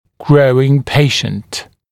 [‘grəuɪŋ ‘peɪʃ(ə)nt][‘гроуин ‘пэйш(э)нт]растущий пациент